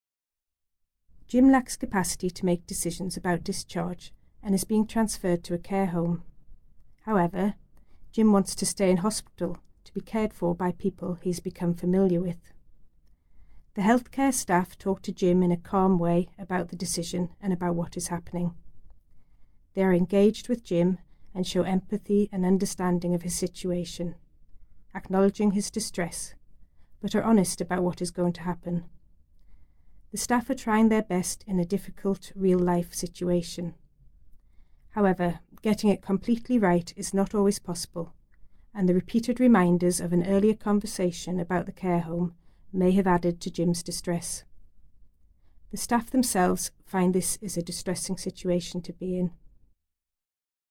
The healthcare staff talk to Jim in a calm way about the decision and about what is happening. They are engaged with Jim and show empathy and understanding of his situation, acknowledging his distress, but are honest about what is going to happen.